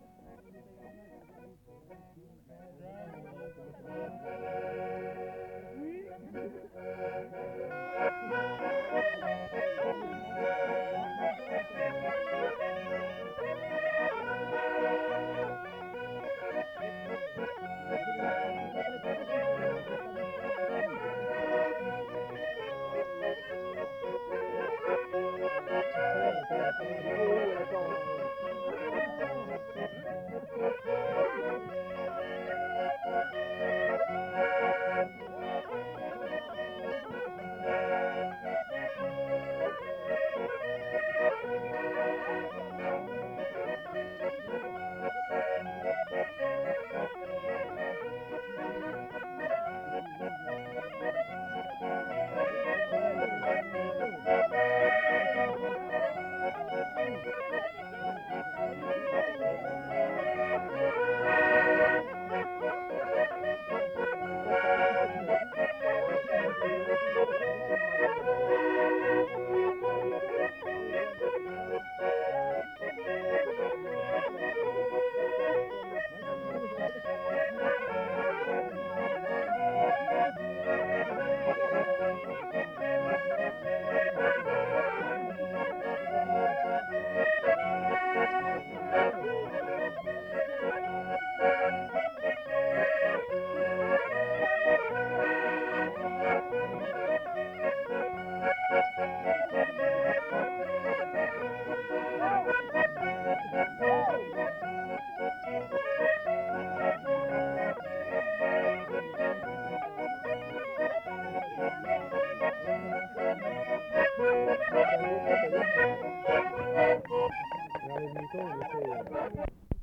Aire culturelle : Viadène
Département : Aveyron
Genre : morceau instrumental
Instrument de musique : cabrette ; accordéon chromatique
Danse : valse